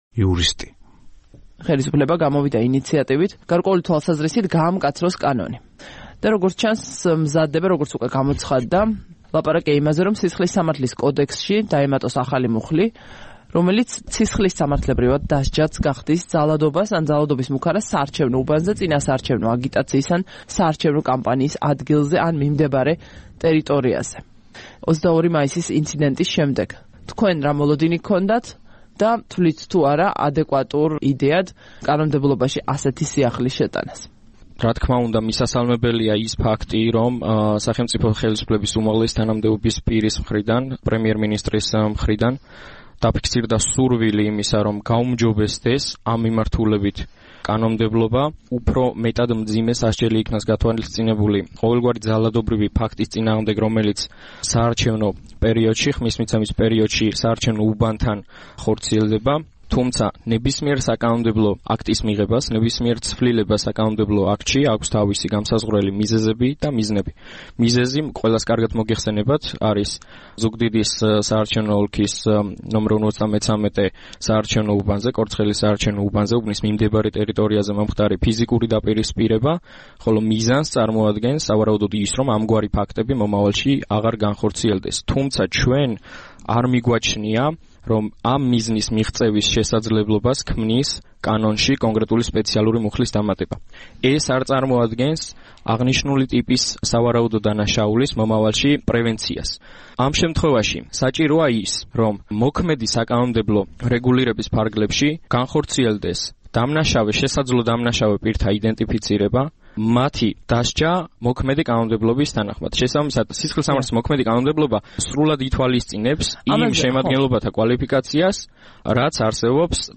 ყოველკვირეული დისკუსია